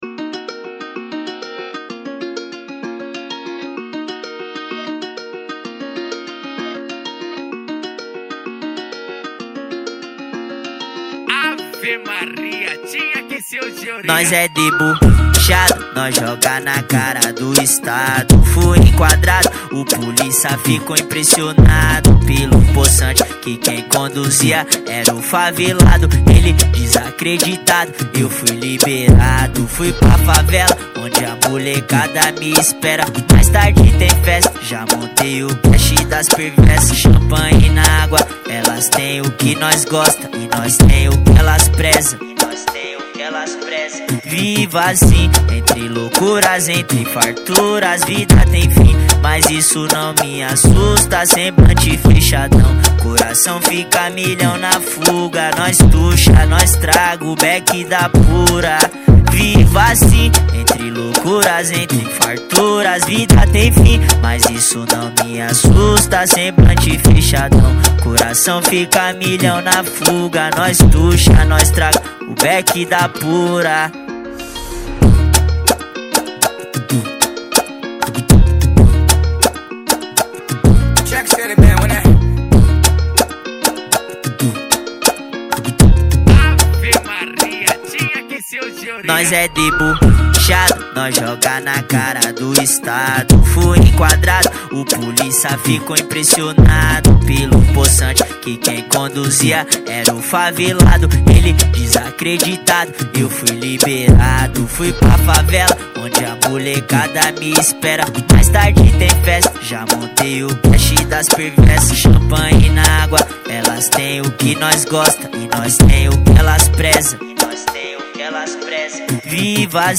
2025-02-22 18:34:16 Gênero: Sertanejo Views